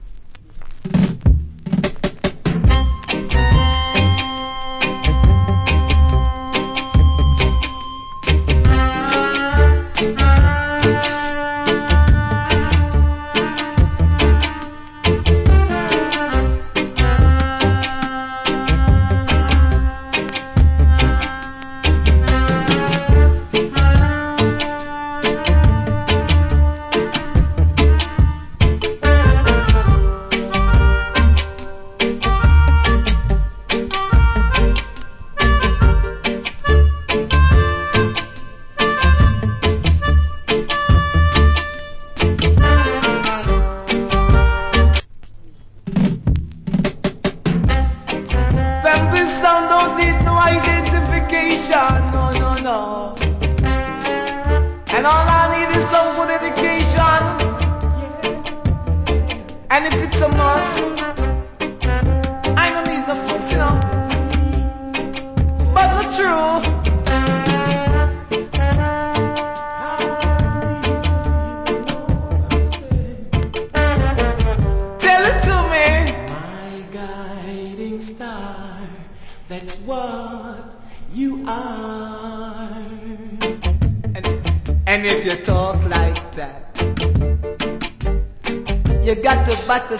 Killer Melodica Inst.